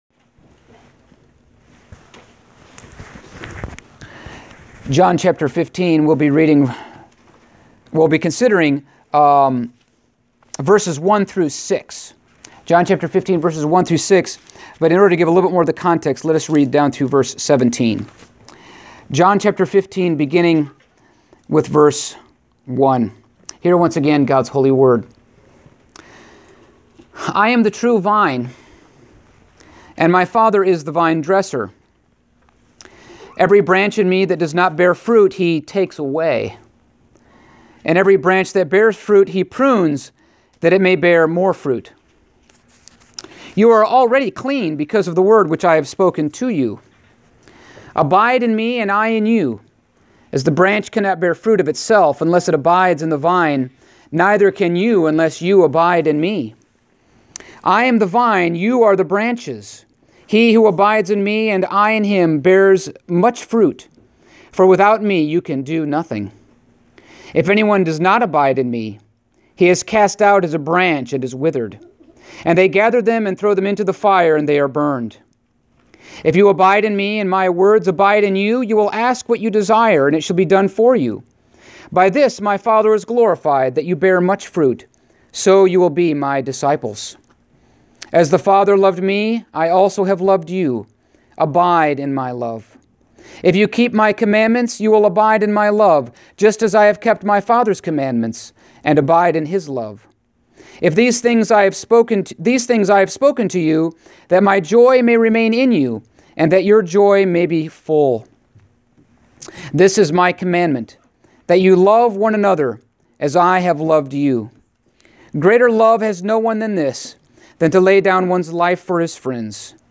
Stand Alone Sermons Passage: John 15:1-6 Service Type: Sunday Morning